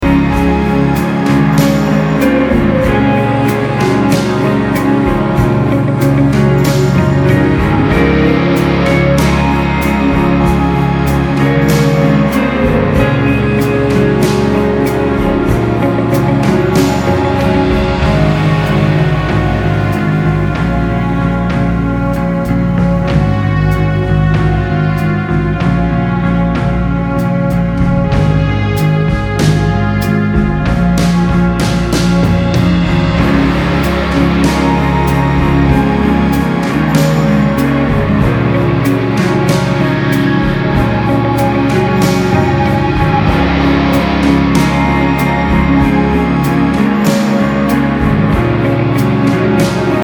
Post Rock, Experimental Rock >